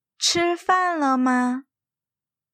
2-1. 発音と書き方
Chī fàn le ma
チー　ファン　ラ　マ